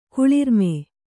♪ kuḷirme